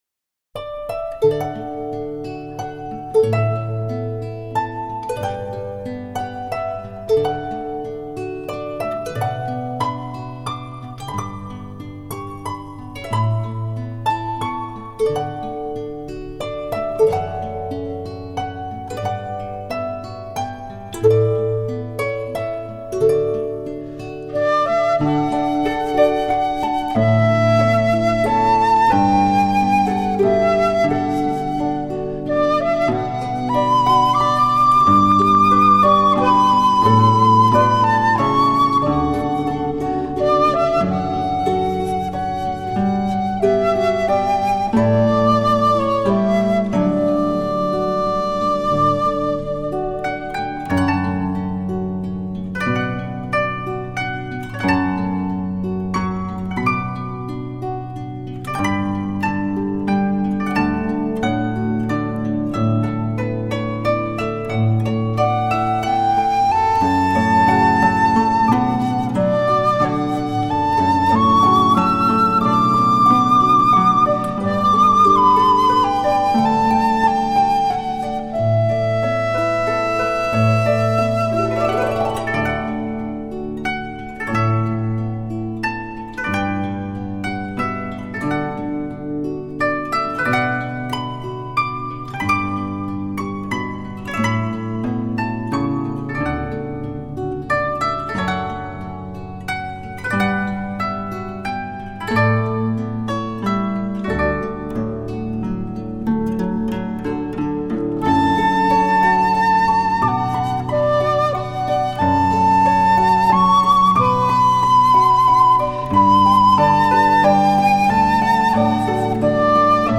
尺八
筝
ギター